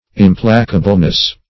Search Result for " implacableness" : The Collaborative International Dictionary of English v.0.48: Implacableness \Im*pla"ca*ble*ness\, n. The quality of being implacable; implacability.
implacableness.mp3